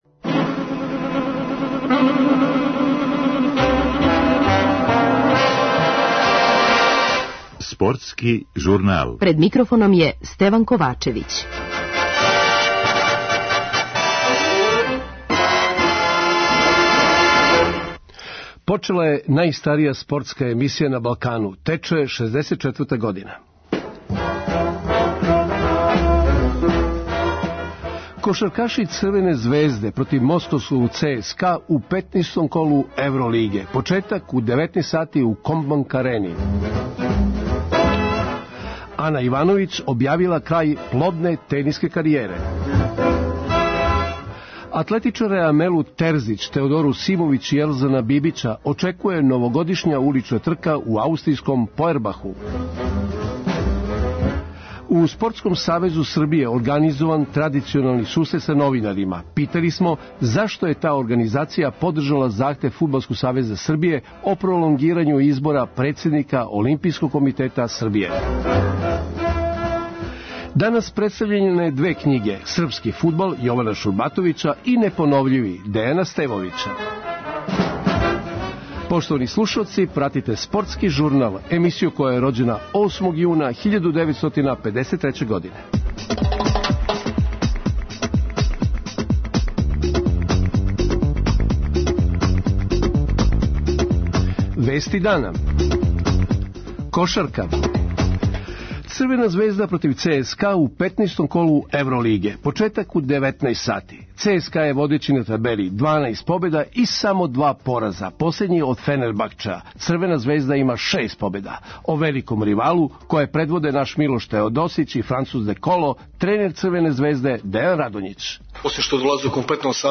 Данас представљене две књиге: Српски фудбал и Непоновљиви, прича о нашим ватерполистима. Са ауторима су разговарали наши репортери.